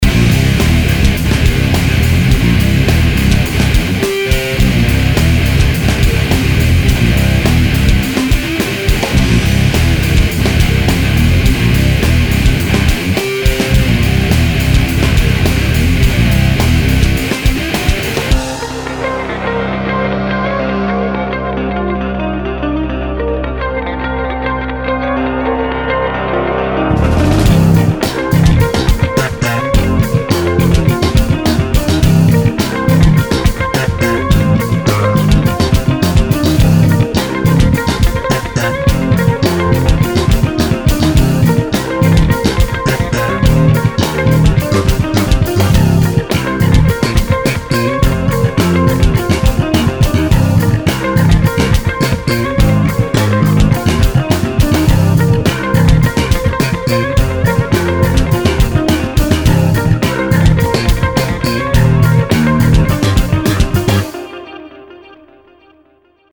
tady v te druhe hraje MTD prvnich 8 taktu toho slapu, co zacina po syntaku. druhych 8 taktu je na A5